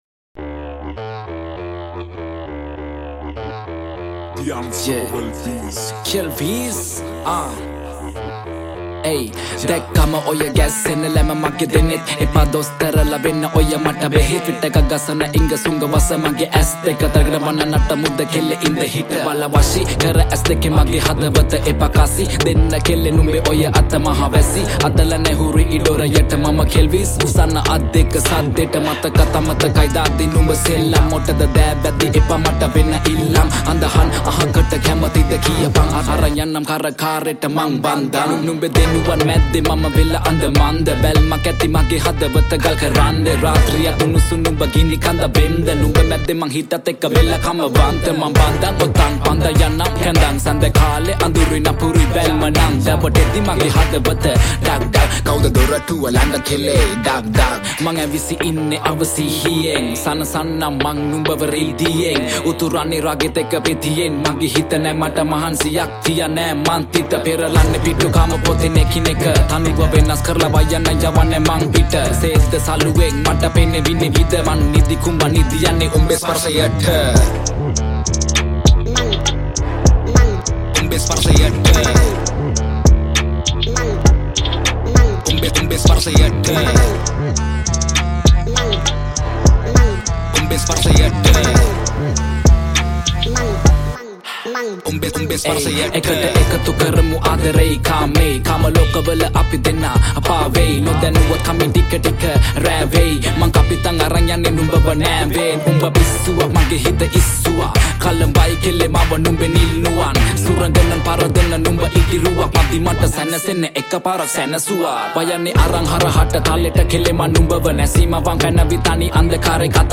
remix
Rap